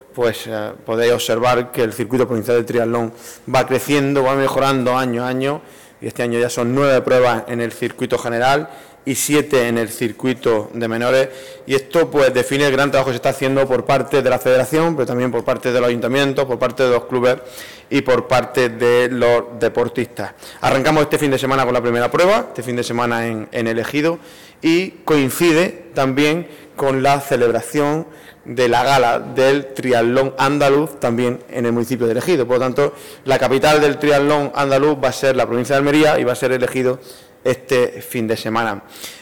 31-01_triatlon_diputado_.mp3